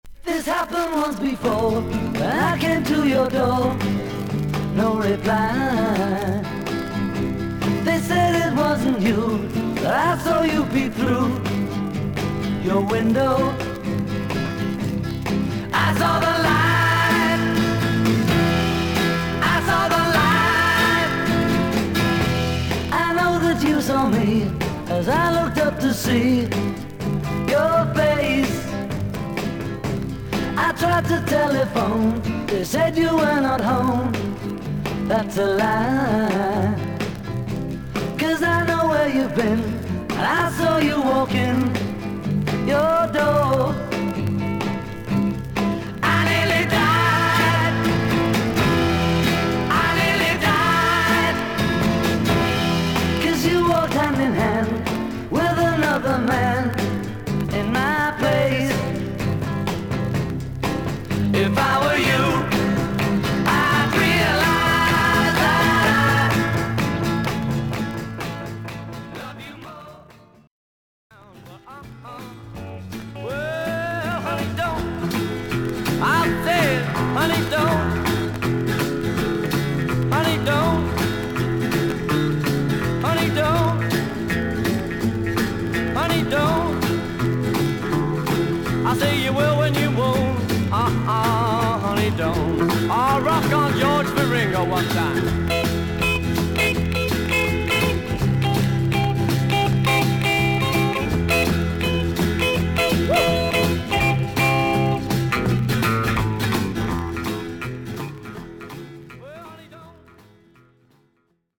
ほかはVG+:盤面に長短のキズ、擦れがあり、全体に大きなサーフィス・ノイズあり。